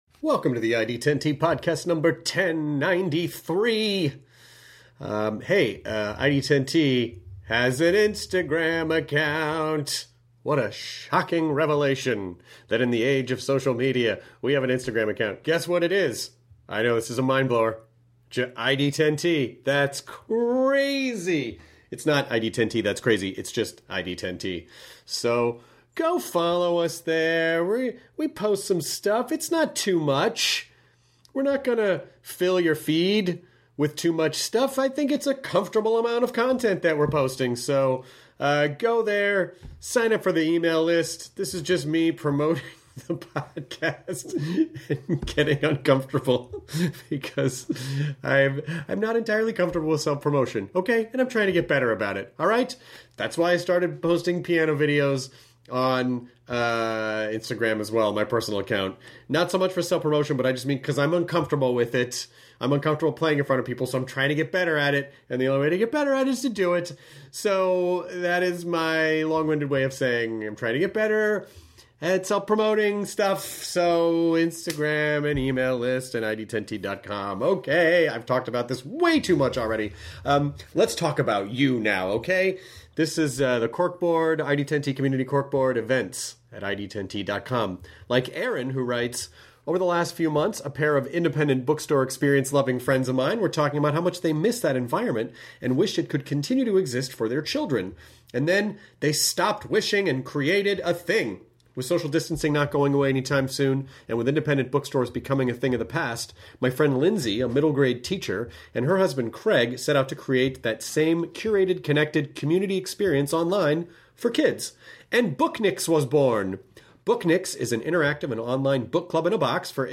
Lamorne Morris (New Girl, Game Night) chats with Chris about taking time to slow down while stuck in quarantine, their shared lack of tattoos, and air travel during a pandemic.